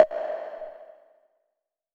Perc  (9).wav